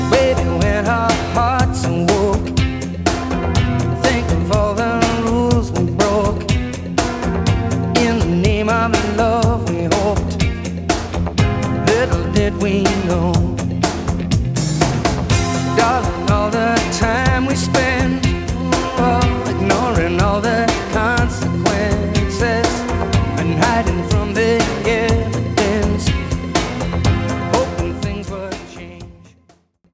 • Качество: 320, Stereo
красивые
рок